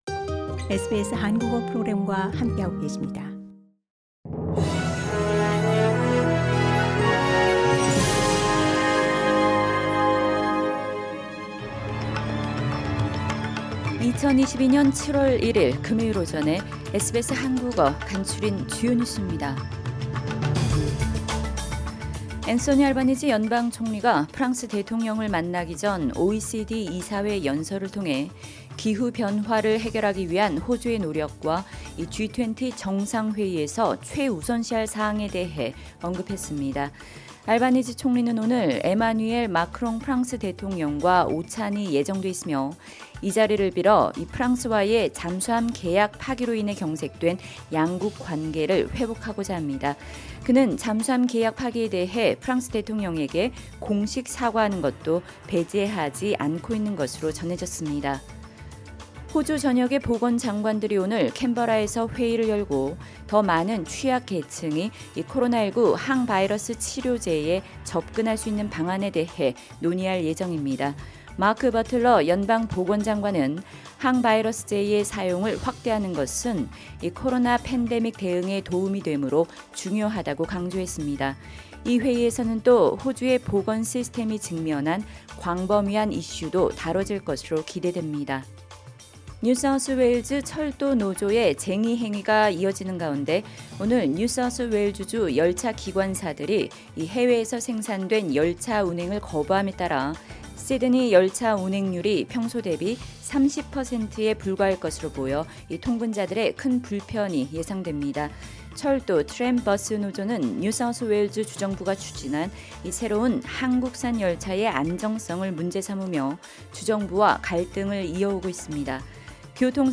SBS 한국어 아침 뉴스: 2022년 7월 1일 금요일